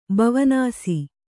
♪ bavanāsi